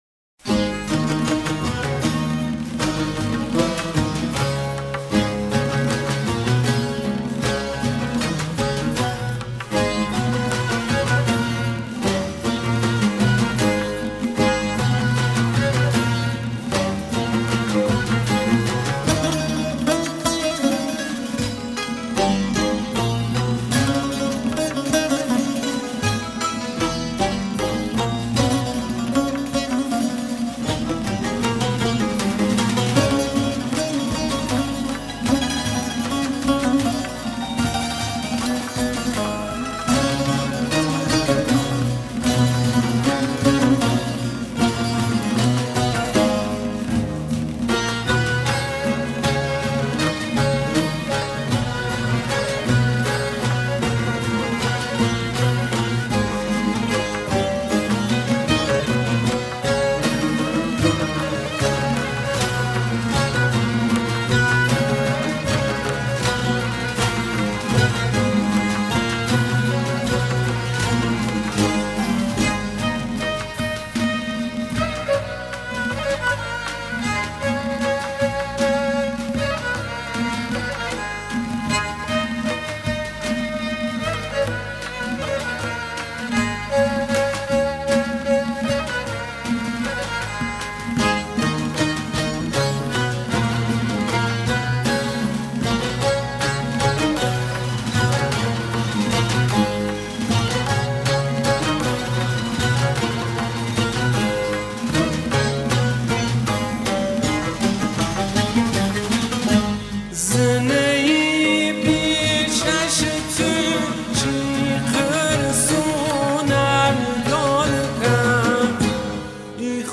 آهنگ لری
Lor music